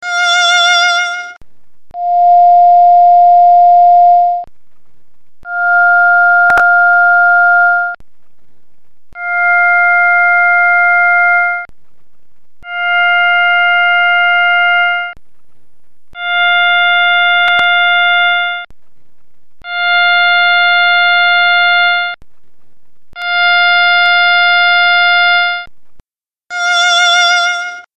til tidspunkt 0.68  Her med vibrato til sidst - sammenligning af 0.44 og 0.68
violin068.mp3